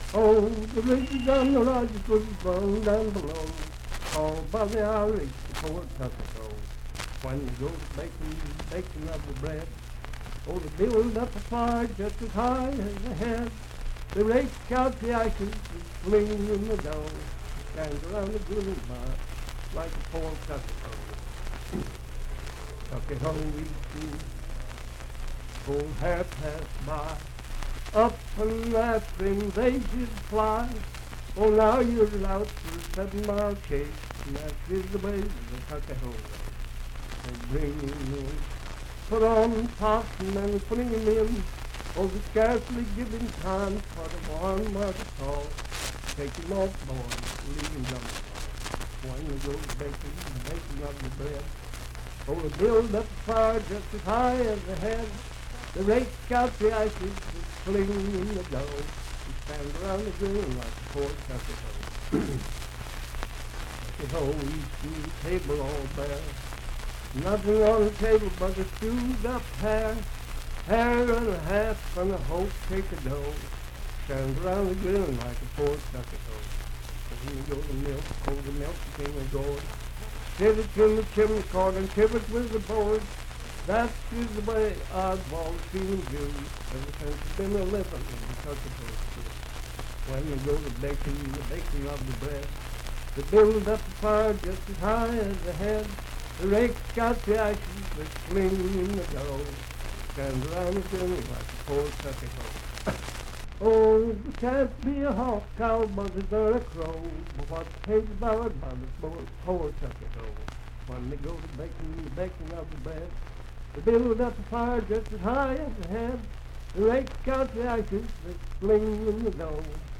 Unaccompanied vocal music
in Mount Storm, W.V.
Verse-refrain 6(2-4) & R(4).
Voice (sung)